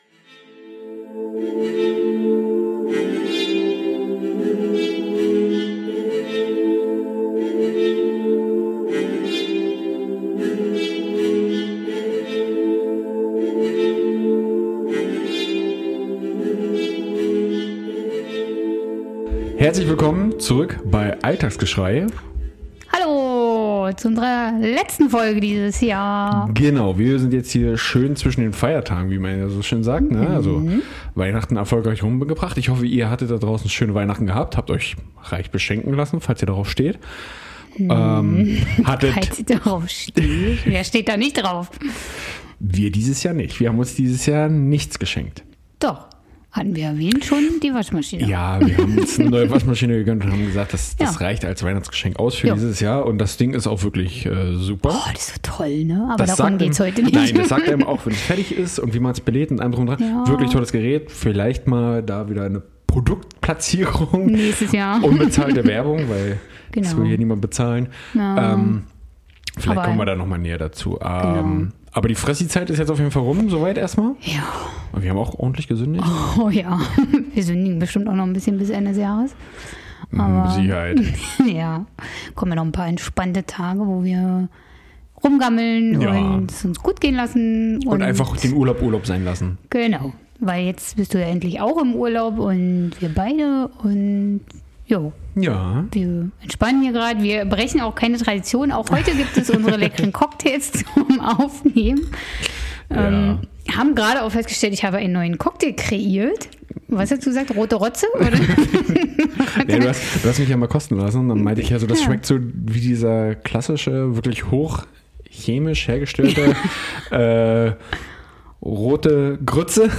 Das und vieles mehr wird natürlich wieder von unseren Songs abgerundet.